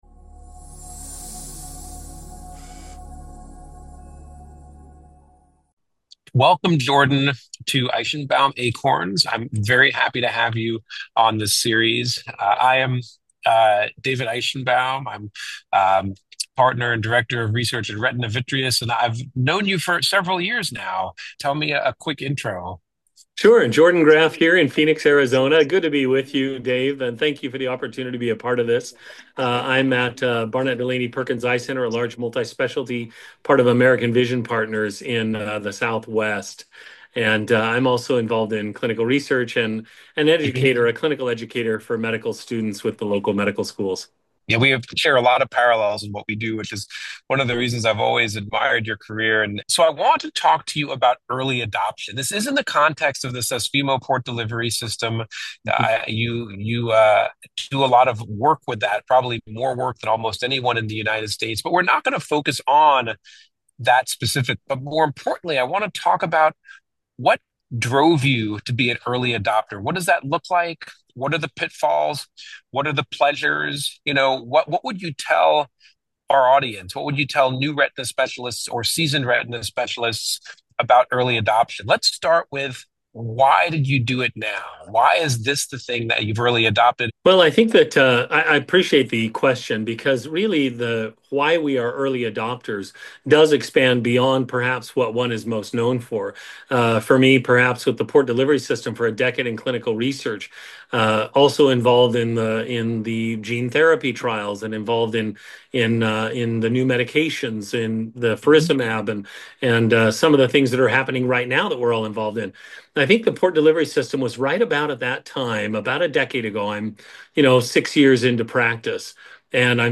This Q&A-style article and podcast series explore the overlooked corners of the retina specialist profession through candid conversations with thought leaders.